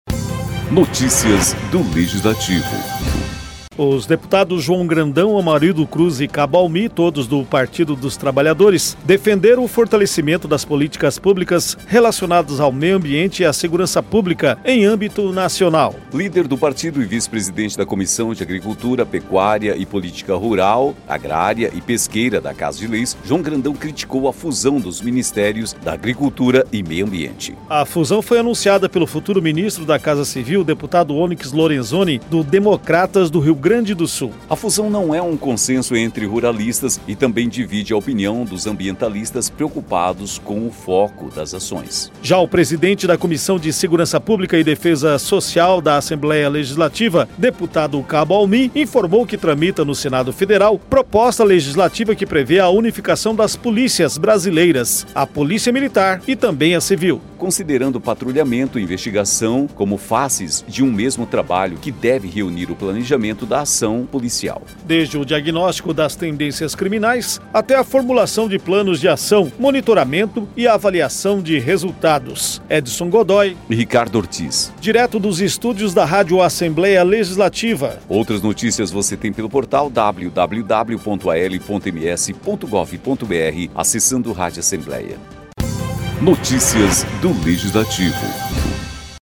Os deputados João Grandão, Amarildo Cruz e Cabo Almi, todos do PT, defenderam o fortalecimento das políticas públicas relacionadas ao Meio Ambiente e à Segurança Pública, em âmbito nacional.